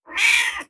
SFX_Cat_Meow_04.wav